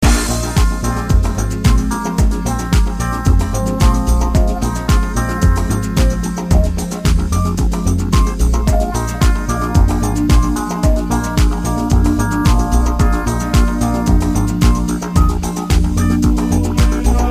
ambient house track.